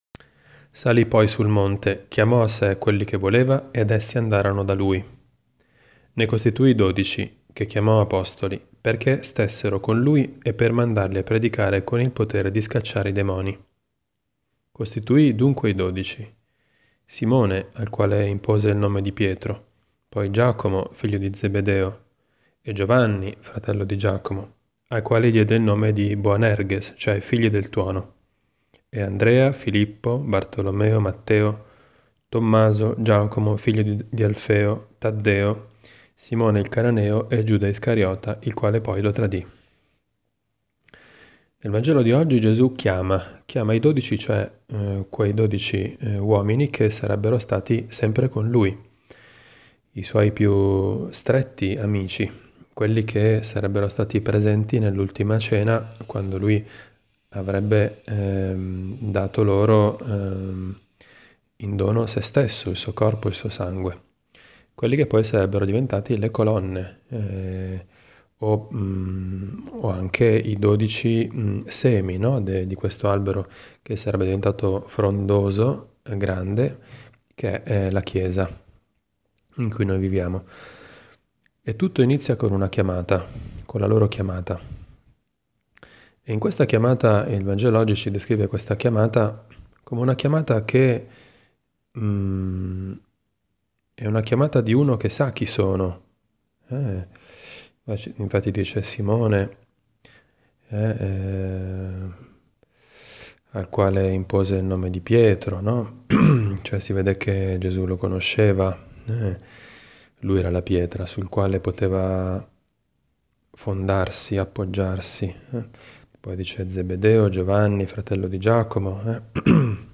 Commento al vangelo (Mc 3,13-19) del 19 gennaio 2018, venerdì della II domenica del Tempo Ordinario.